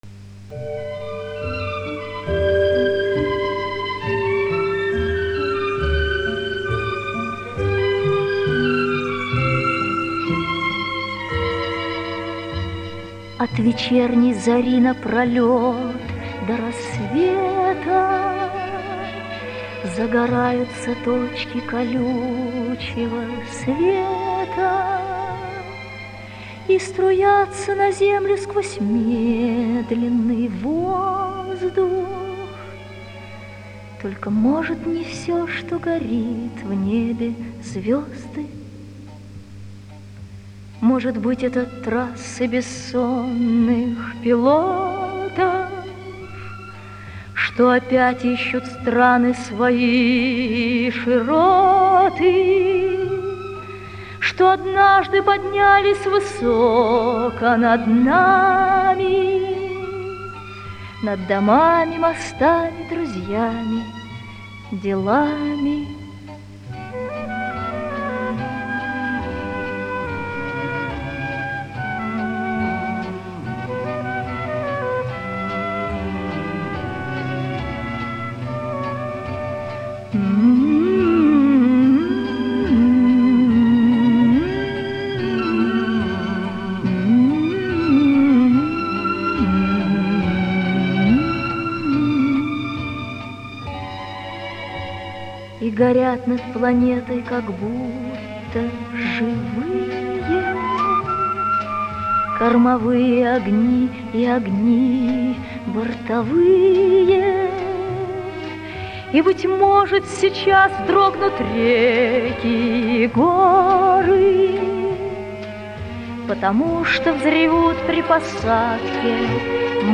Та начиналась почти без музыки, медленным речитативом.